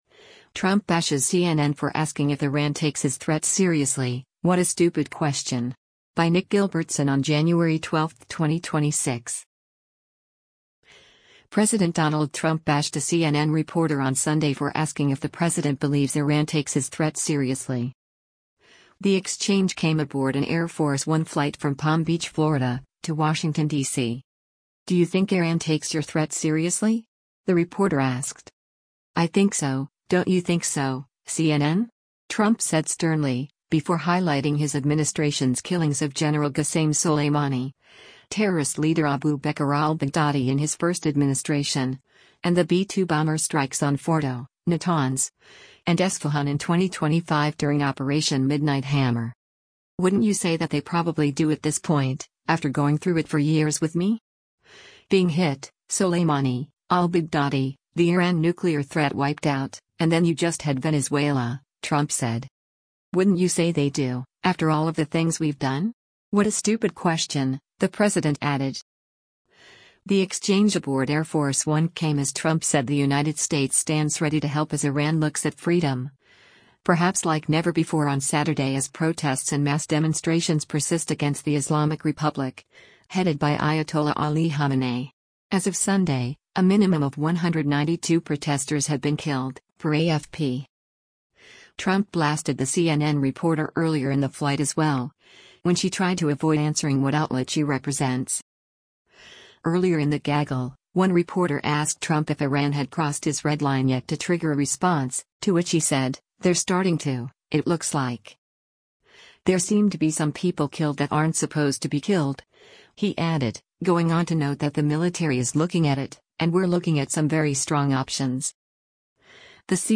The exchange came aboard an Air Force One flight from Palm Beach, Florida, to Washington, DC.
“I think so, don’t you think so, CNN?” Trump said sternly, before highlighting his administration’s killings of Gen. Qasem Soleimani, terrorist leader Abu Bakr al-Baghdadi in his first administration, and the B-2 bomber strikes on Fordow, Natanz, and Esfahan in 2025 during operation Midnight Hammer.